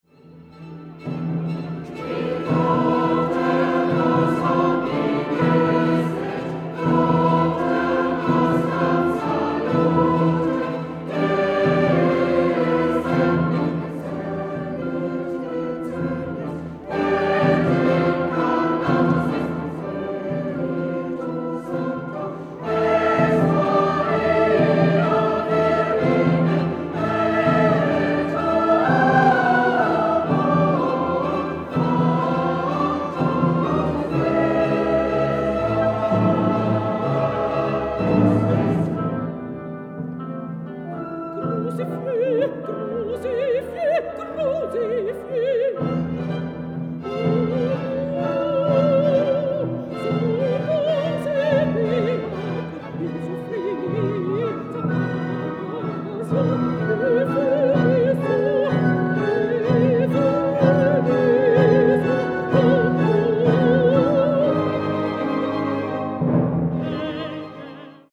für soli, chor und kleines sinfonieorchester